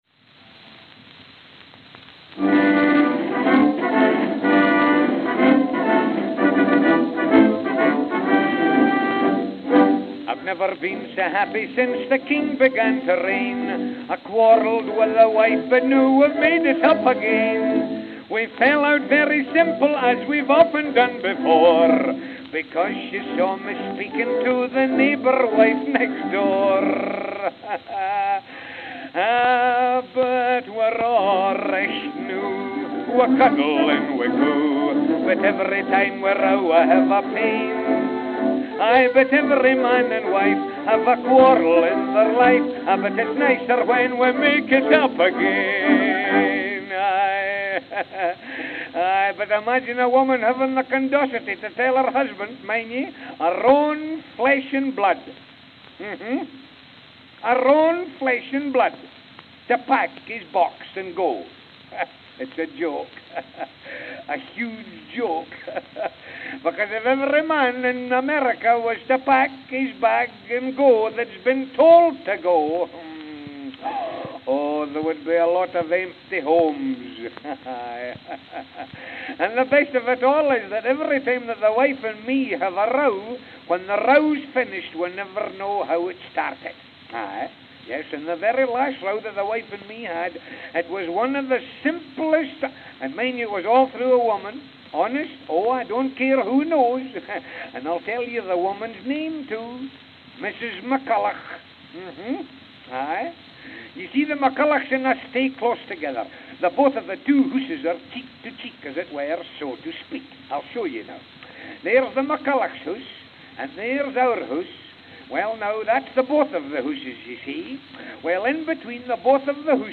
November 20, 1915 (New York, New York) (4/4)